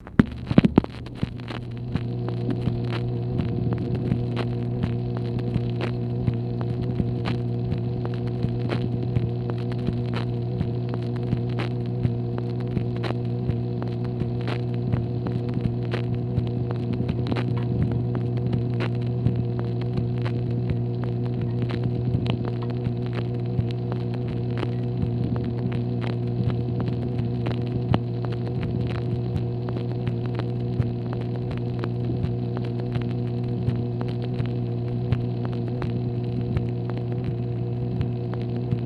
MACHINE NOISE, May 18, 1965